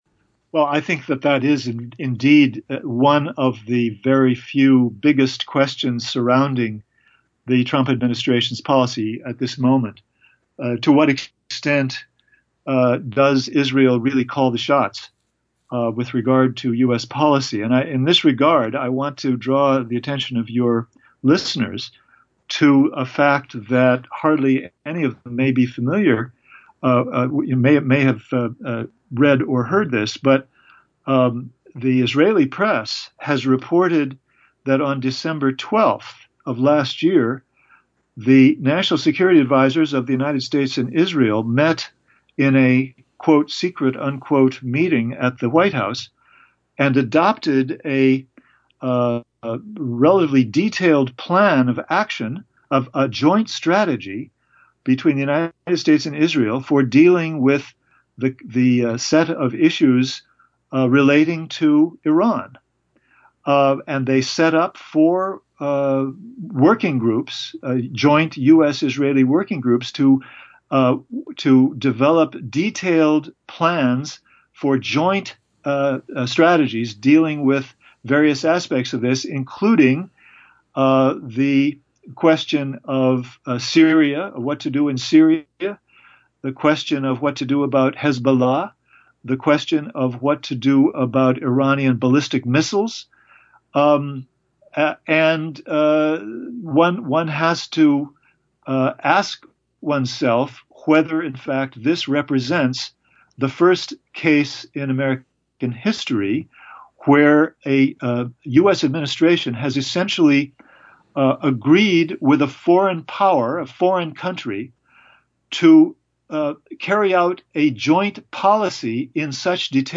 In-Depth Interview: Iran Expert Gareth Porter on America’s Most Dangerous Hawk, John Bolton